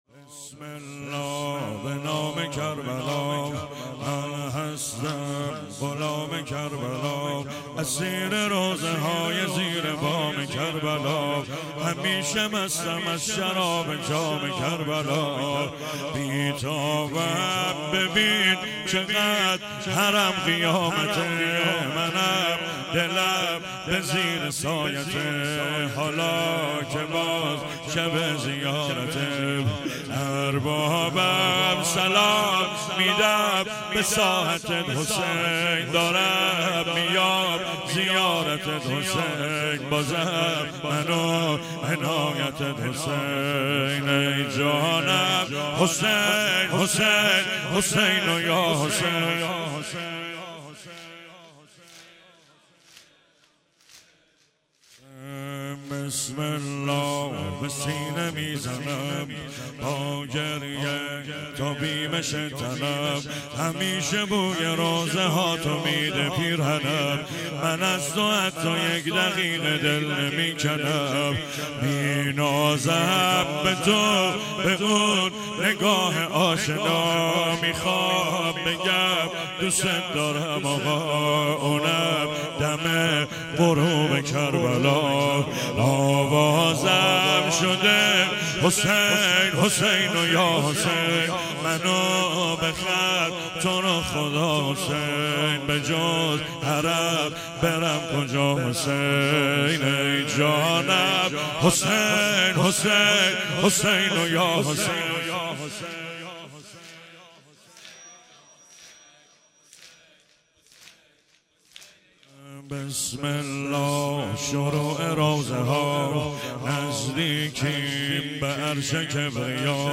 زمینه | بسم الله به نام کربلا
مداحی
مراسم استقبال از ماه محرم الحرام